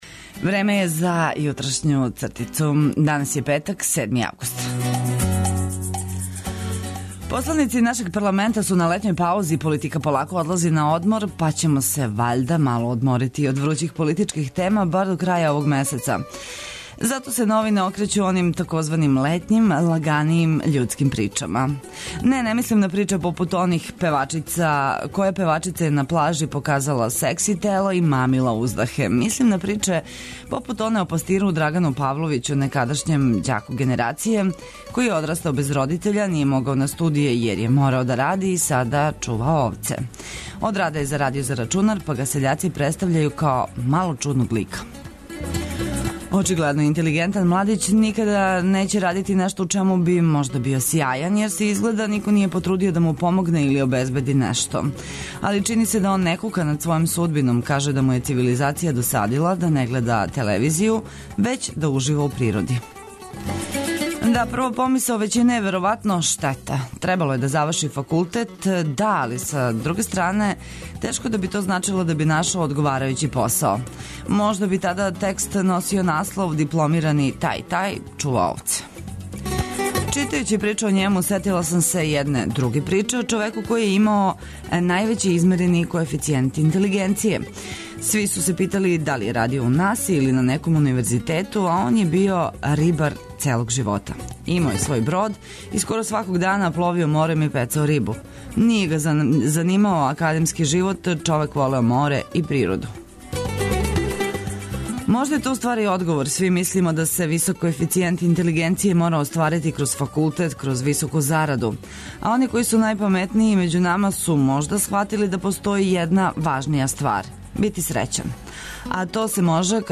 Хватамо јутарњу свежину од ране зоре. Последњи радни дан ове недеље будимо се уз понеку шалу, добру музику и корисне информације.